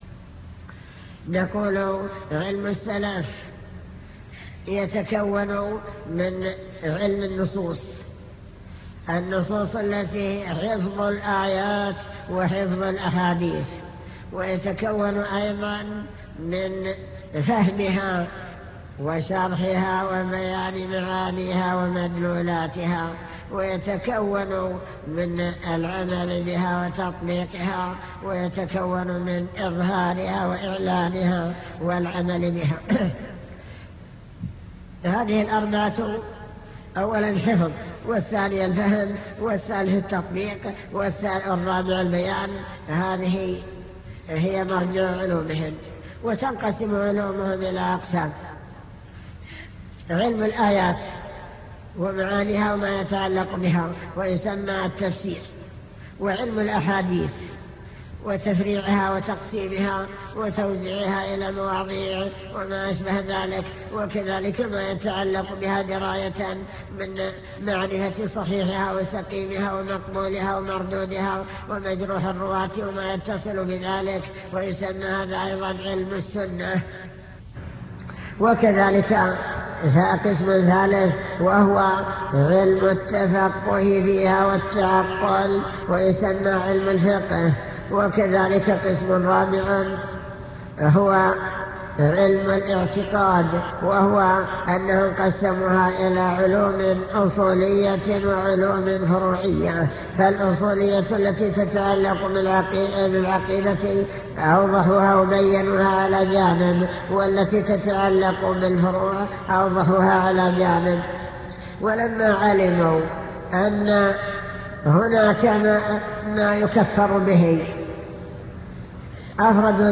المكتبة الصوتية  تسجيلات - محاضرات ودروس  السلف الصالح بين العلم والإيمان